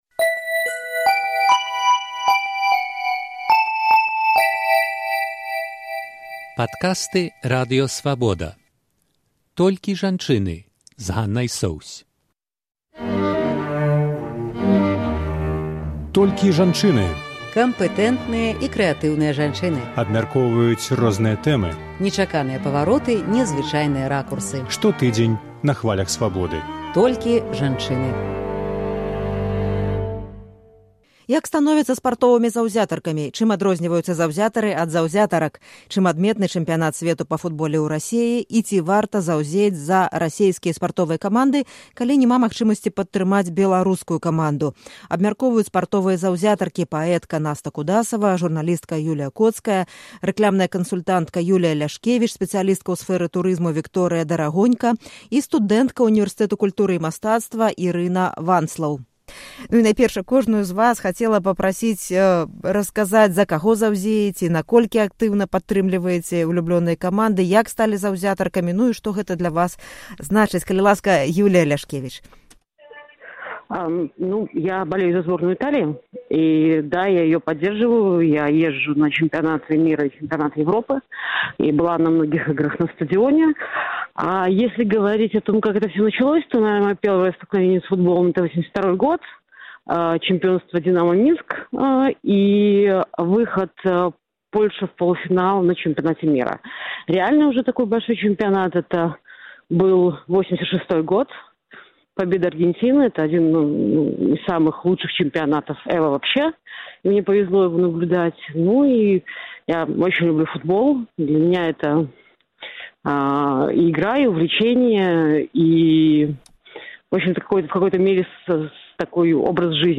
Чым адрозьніваюцца заўзятары ад заўзятарак? Абмяркоўваюць спартовыя заўзятаркі